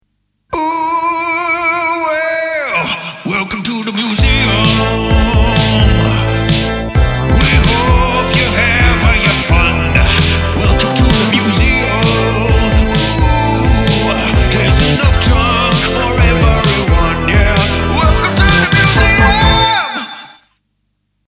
here is a rough edit of tomorrows video, aswell as the museum jingle before and after it went through the tape machine
Fantastic 80's Jingle!!, but the normal version is different from tape machine, the version of tape machine have a clap sample, we prefer it, is it possible to have it in high quality?
hey up the clap is there.the telephone managed to pick it up more, so I have made a version with the clap louder its attached above:)